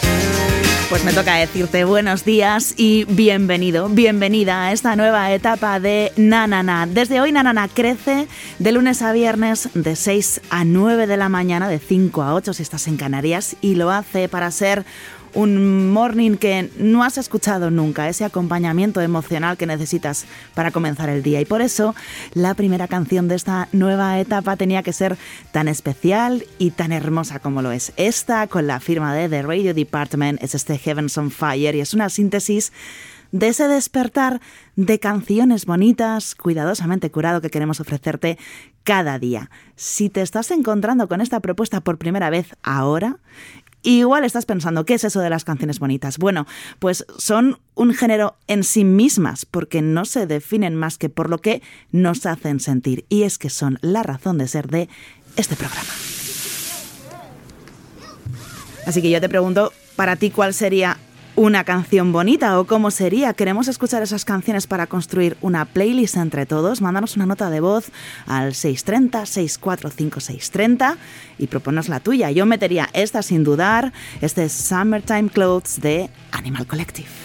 Musical
Fragment extret de RNE audio.